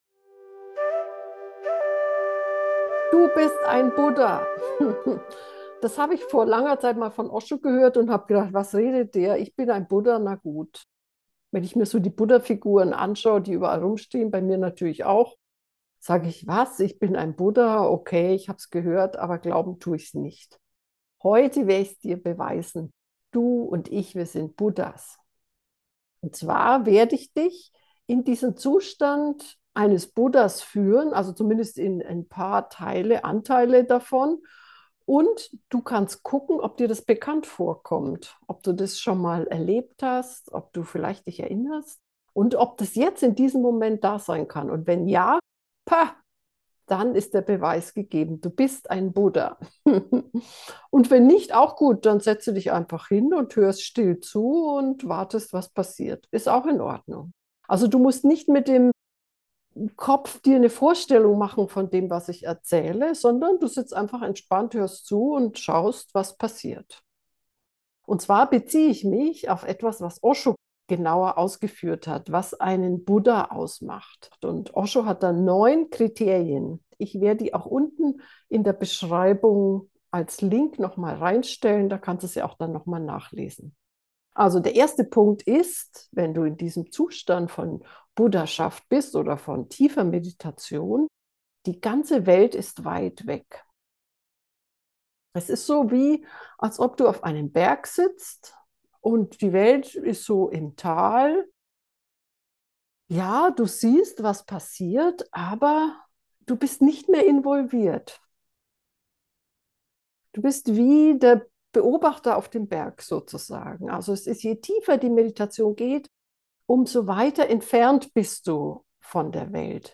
Die geführte Meditation ist mit offenen Ende, du kannst also noch ewig weiter still in deiner Buddhanatur sitzen.